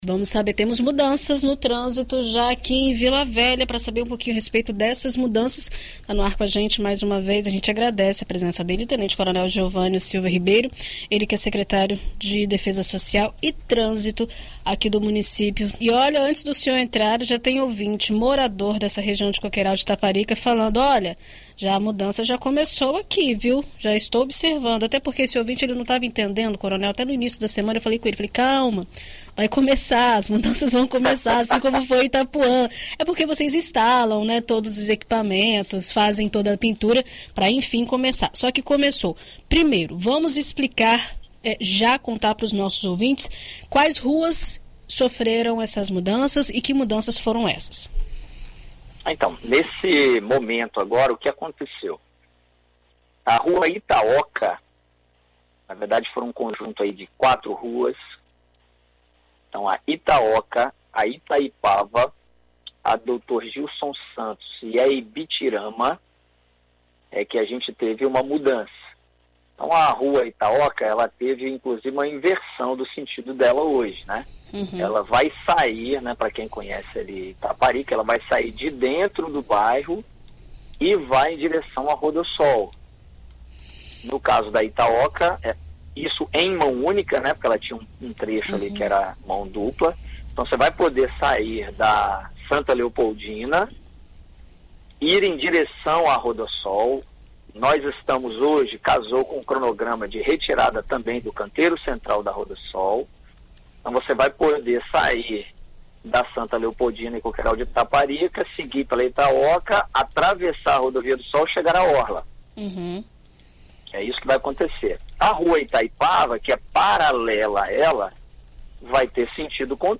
Em entrevista à BandNews FM Espírito Santo nesta quarta-feira (29), o secretário de Defesa Social e Trânsito do município, tenente-coronel Geovanio Silva Ribeiro, detalha as alterações realizadas e como será o funcionamento do fluxo de veículos pelas ruas.